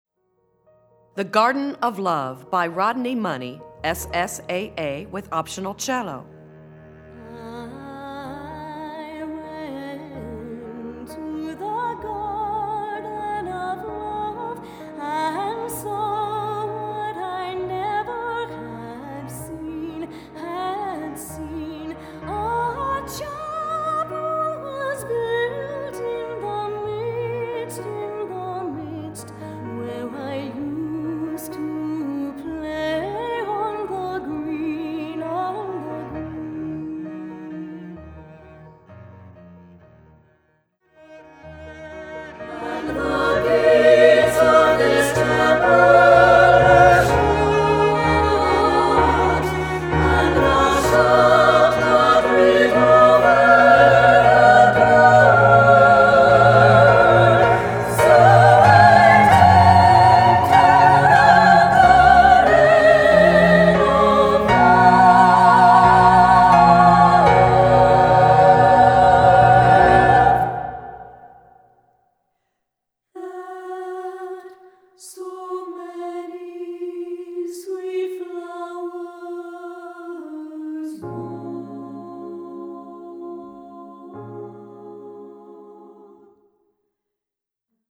lawson gould choral